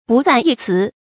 不贊一詞 注音： ㄅㄨˋ ㄗㄢˋ ㄧ ㄘㄧˊ 讀音讀法： 意思解釋： 一句話也不說。